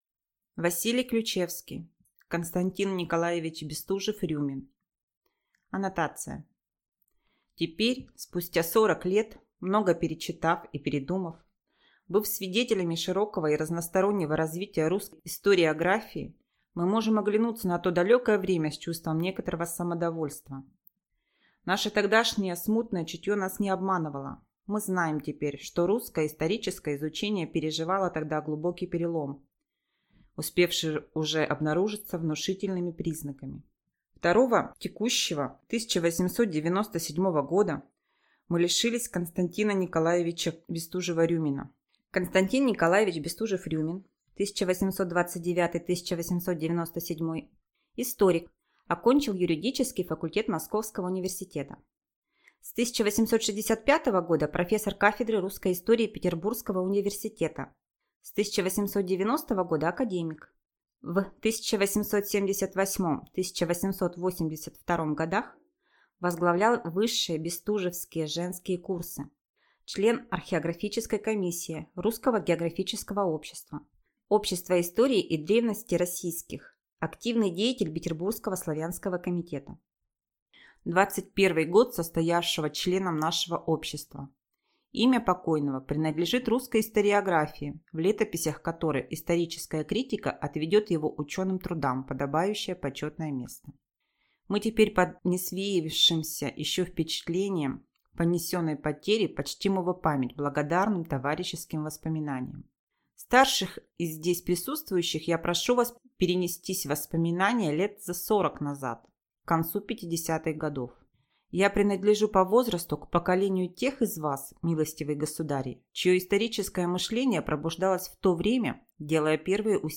Аудиокнига К.Н. Бестужев-Рюмин | Библиотека аудиокниг